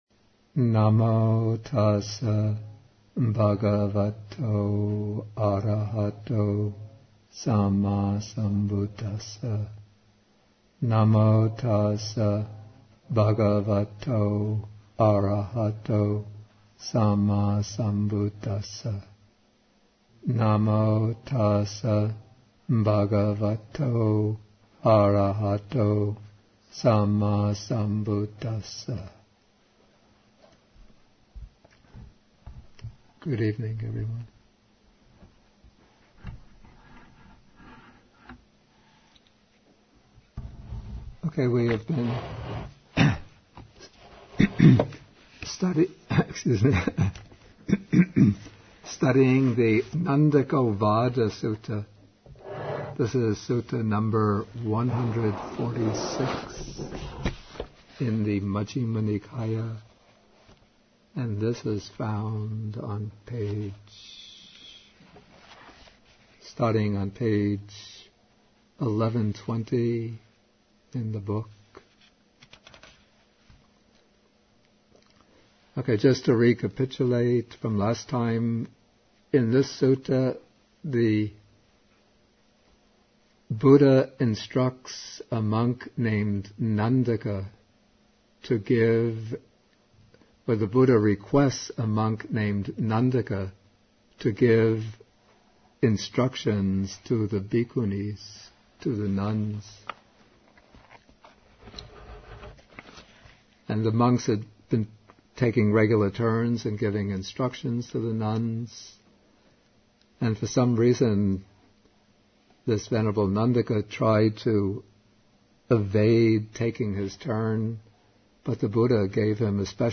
MN146, Bhikkhu Bodhi at Bodhi Monastery (lectures 98 and 99)